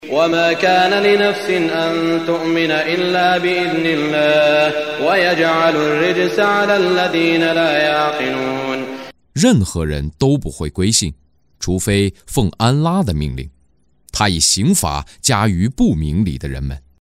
中文语音诵读的《古兰经》第（优努斯）章经文译解（按节分段），并附有诵经家沙特·舒拉伊姆的诵读